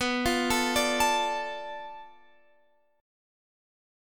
Listen to Bm7b5 strummed